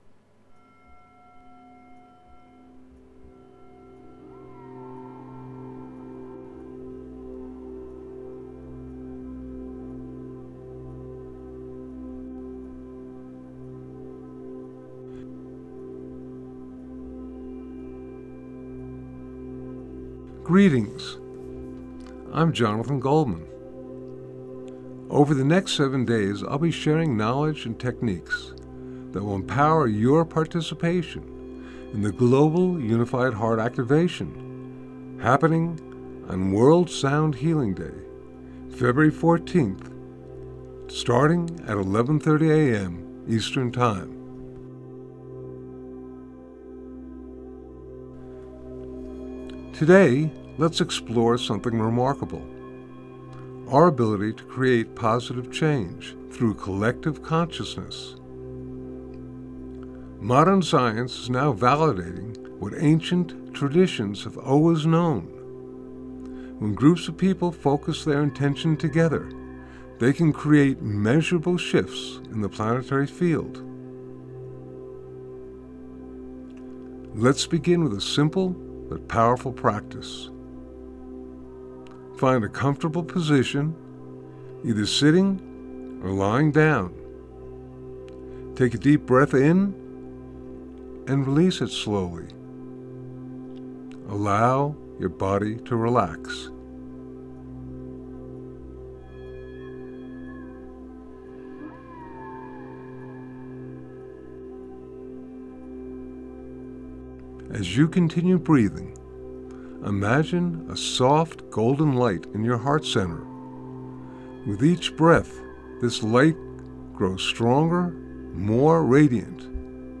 The session includes a guided meditation focusing on controlled breathing and visualization to enhance our connection collective consciousness to create a global web of healing vibration.